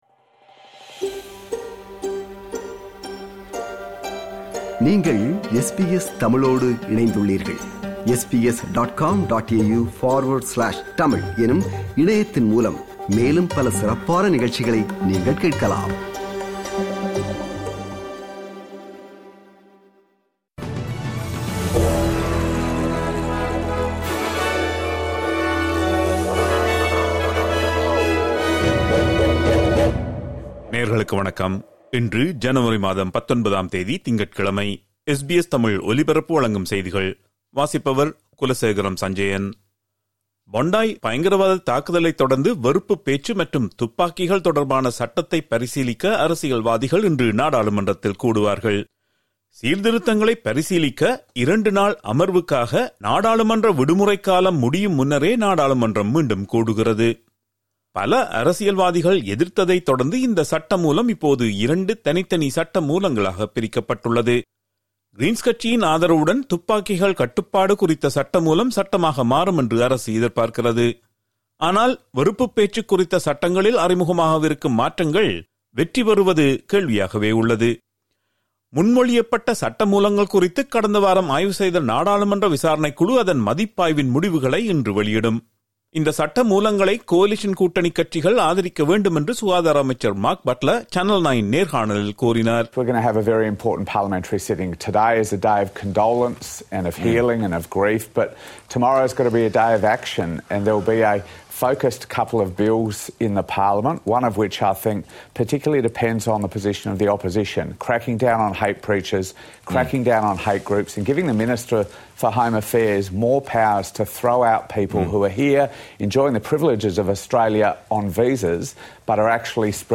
SBS தமிழ் ஒலிபரப்பின் இன்றைய (திங்கட்கிழமை 19/01/2026) செய்திகள்.